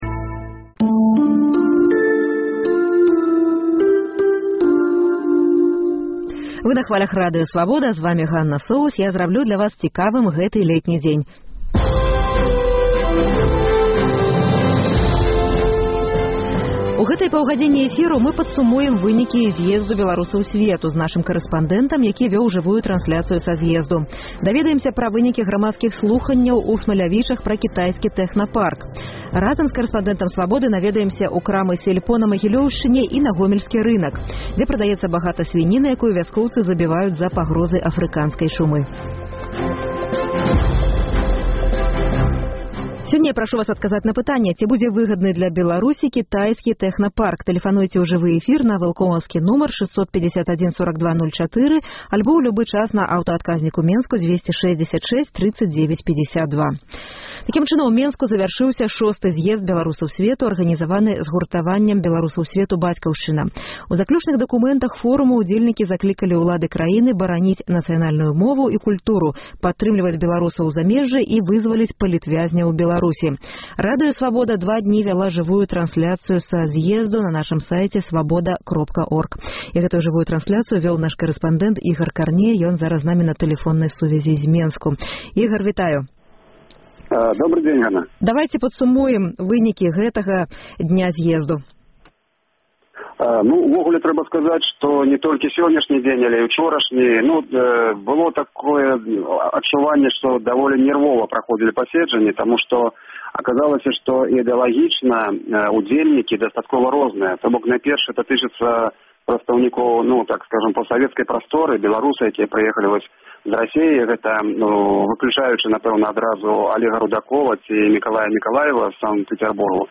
Вас чакаюць жывыя ўключэньні карэспандэнтаў «Свабоды» з шостага зьезду беларусаў сьвету і грамадзкіх слуханьняў пра кітайскі тэхнапарк са Смалявічаў То Чарнобыль, то сьвіная чума — рэпартаж з гомельскага рынку. Дасьледаваньне пра беларускае «сяльпо». Паўмільёна даляраў хабару для беларускага чыноўніка.